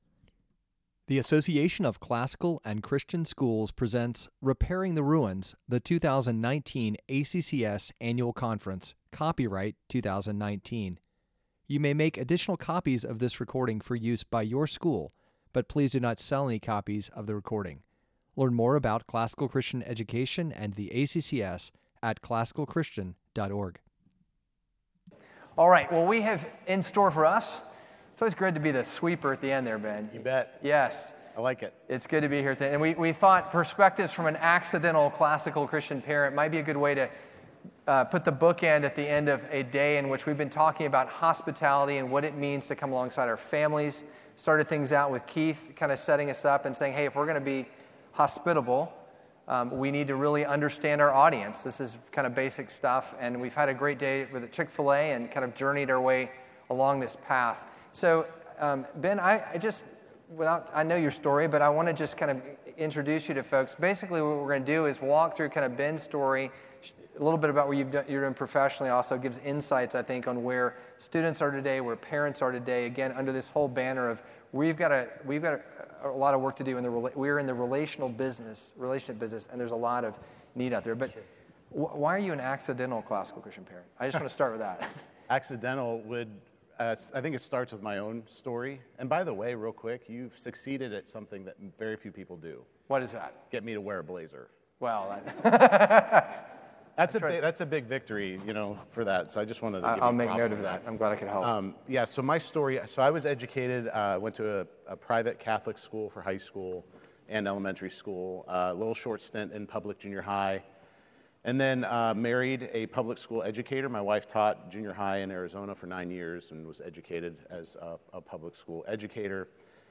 2019 Leaders Day Talk | 36:00 | Leadership & Strategic